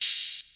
Open Hat 1 [ basic ].wav